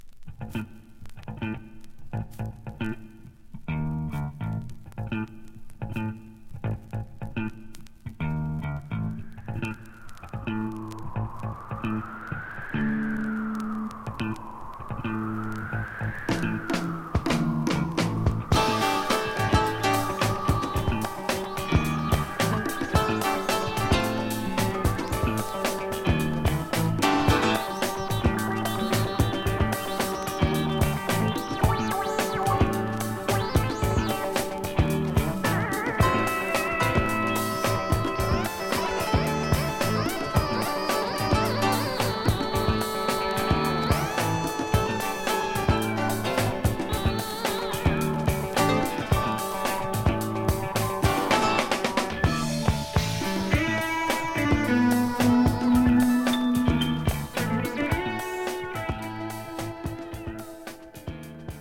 ベネズエラ・ファンク・プロッグ・ロック。